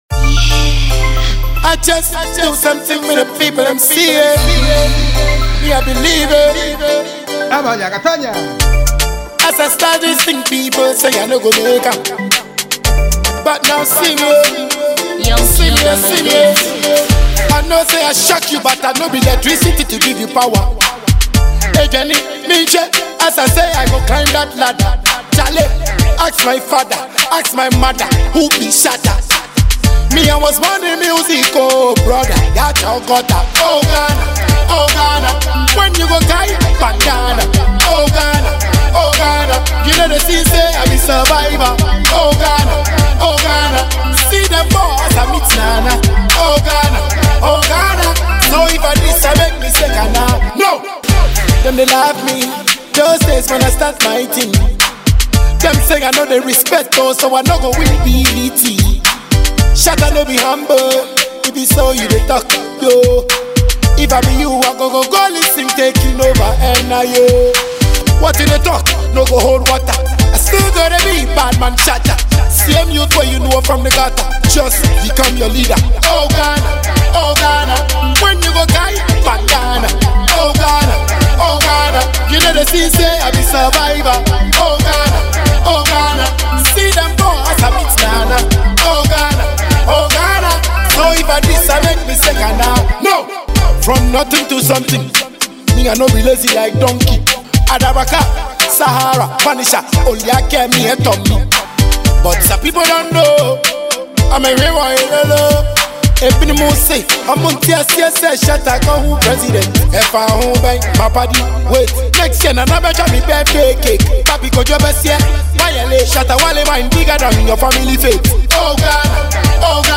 Hiplife